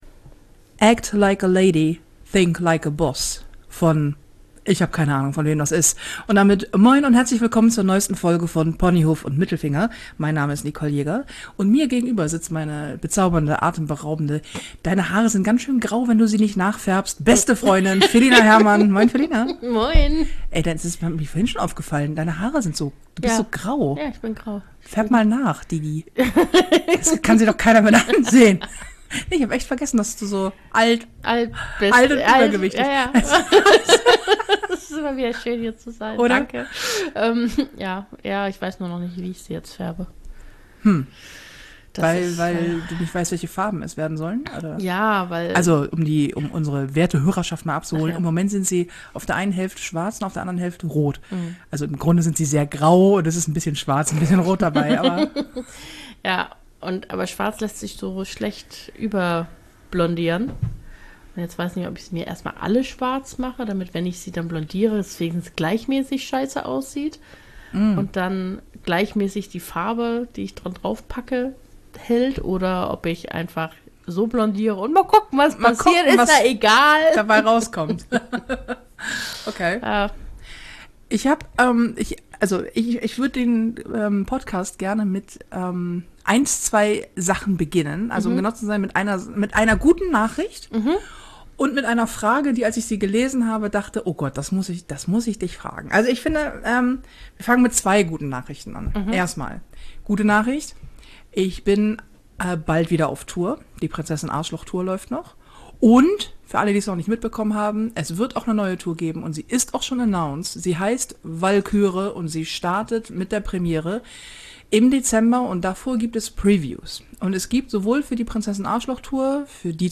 Ein Podcast so flauschig wie die Couch, von der aus zwei sehr unterschiedliche Frauen die Welt beleuchten.